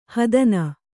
♪ hadana